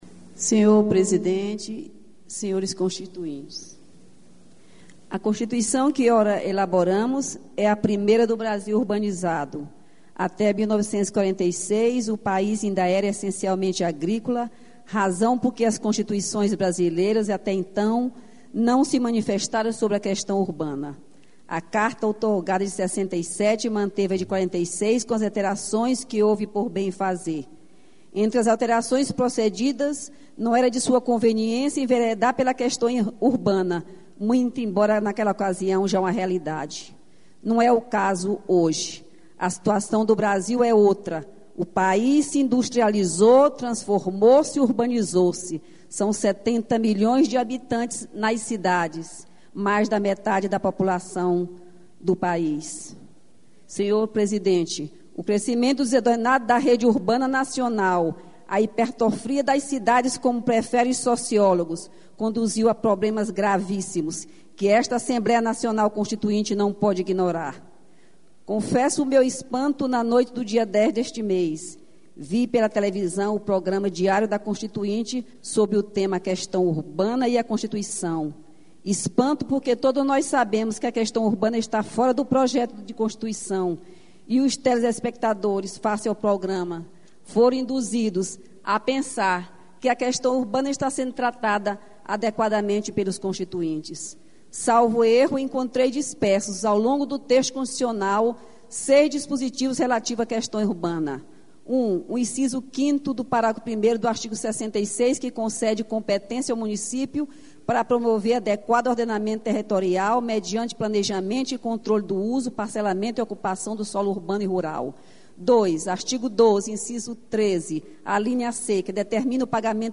- Discurso pronuniado em 21 de agosto de 1987 – Questão urbanaDiscute, em primeiro turno, o Projeto de constituição. A questão urbana; o tratamento do tema na Subcomissão da Questão Urbana e na Comissão da Ordem Econômica; a autonomia municipal; ordenação territorial e utilização do solo urbano; subordinação do interesse individual ao coletivo; competência ao Estado e ao Município para o controle sobre o uso do solo urbano; desapropriação do solo urbano; migrações internas; transporte de massa.